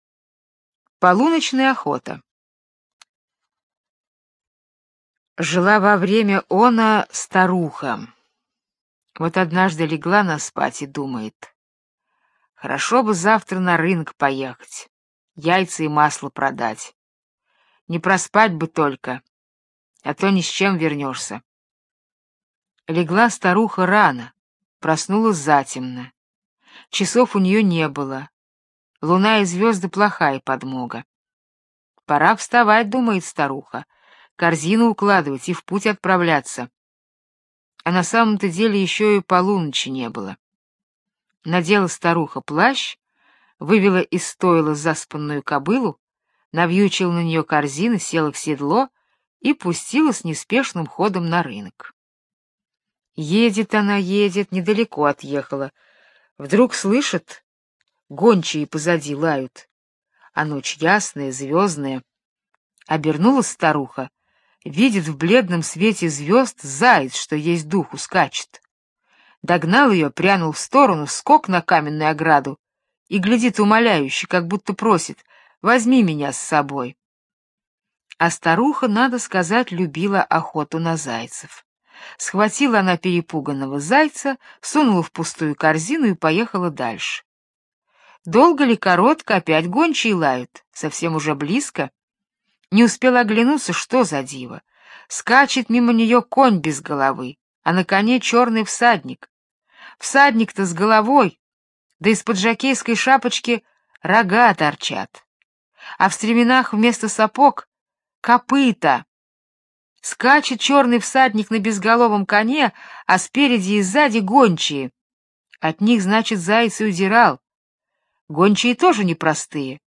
Полуночная охота - британская аудиосказка - слушать онлайн